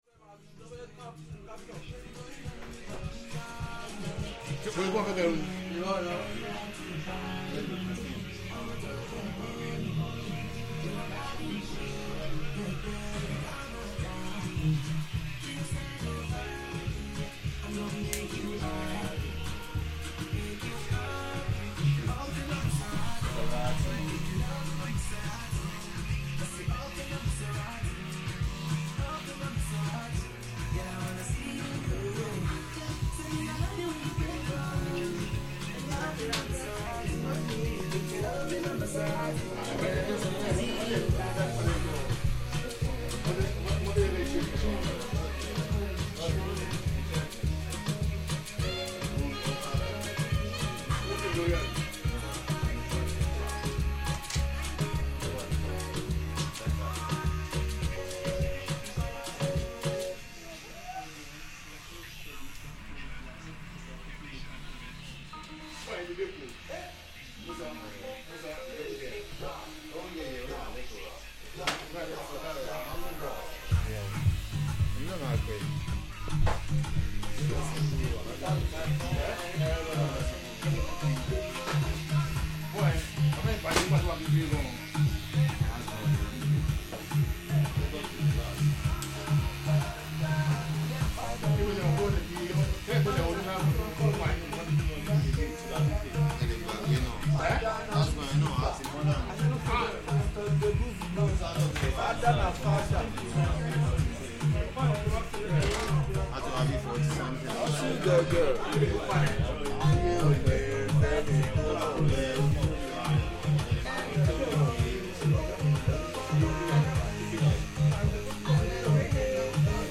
The sound of a traditional west African barber’s shop in Peckham, serving mostly customers from all over the continent who now live in London.